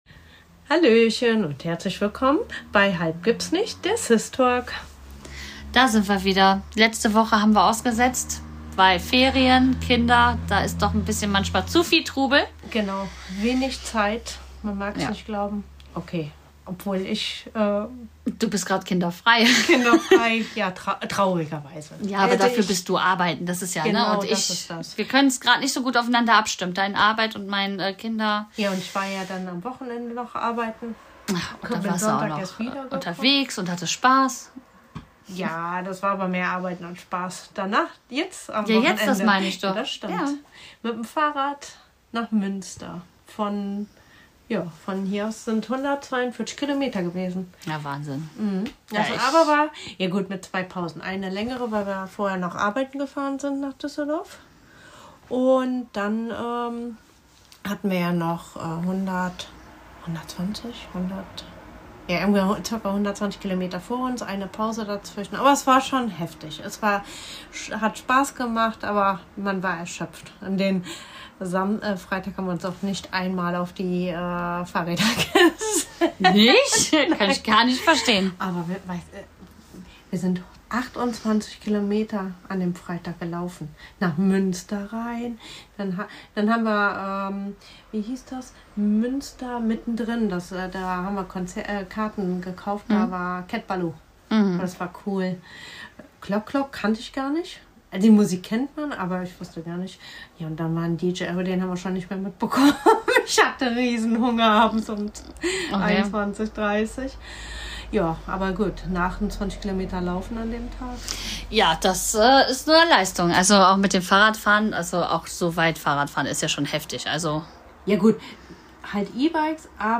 Zwei Schwestern, viele Themen: Von Kindern und Pubertät über Urlaub und gutes Essen bis hin zu Tattoos und Alltagschaos. Ehrlich, lustig und mitten aus dem Leben – ein Podcast wie ein Plausch am Küchentisch.